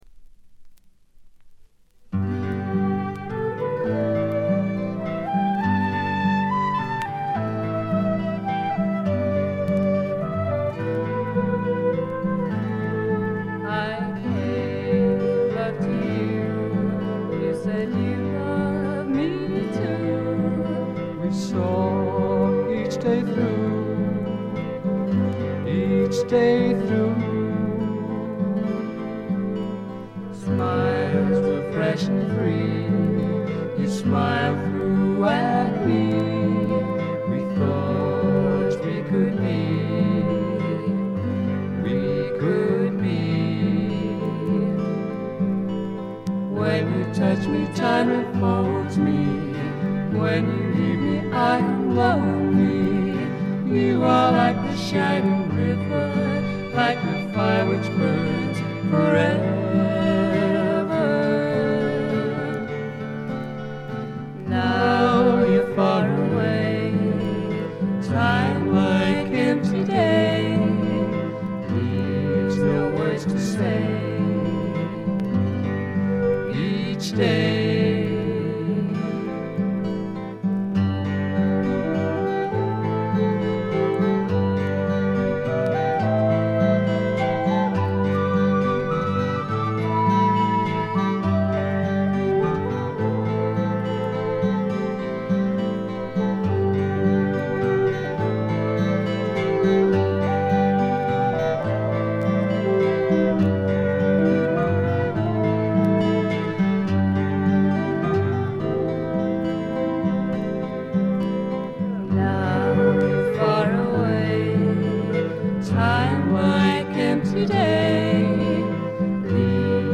ところどころでチリプチやバックグラウンドノイズ。
試聴曲は現品からの取り込み音源です。